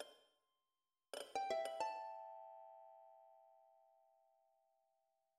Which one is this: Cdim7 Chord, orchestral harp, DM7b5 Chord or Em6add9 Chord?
orchestral harp